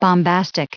added pronounciation and merriam webster audio
116_bombastic.ogg